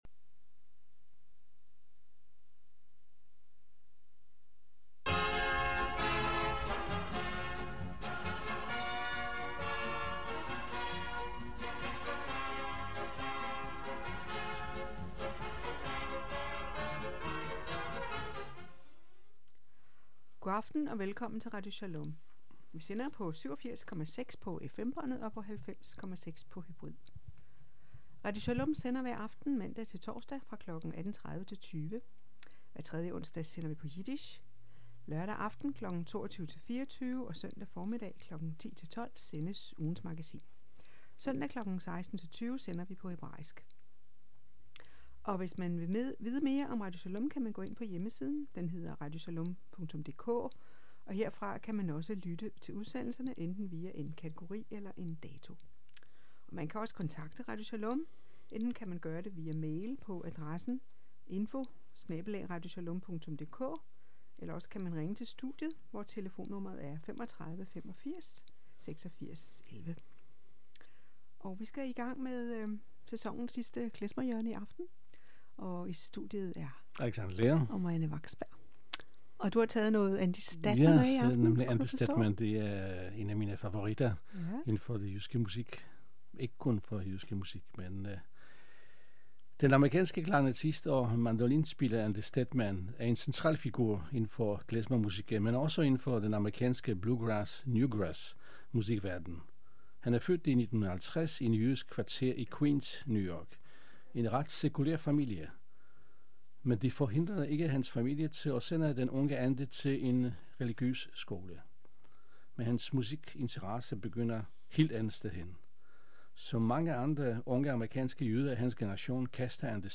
klezmer hjørne